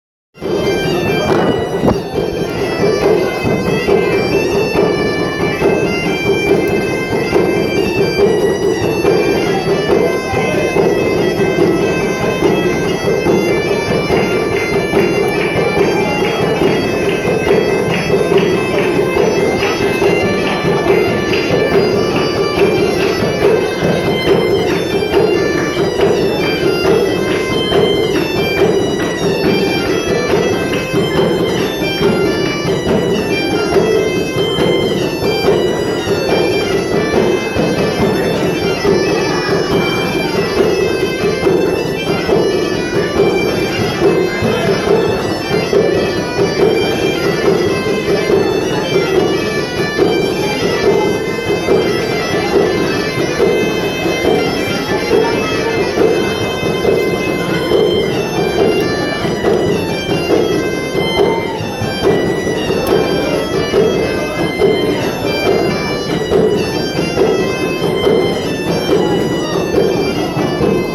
C’est incroyablement fort sous l’arche, particulièrement les cornemuses, et ayant l’ouïe fine, je dois boucher mes oreilles à chaque fois que je passe.
À part le volume, c’est habituellement bon cependant et offre une succulente saveur auditive galicienne:
Ouïe: La cornemuse sous l’arche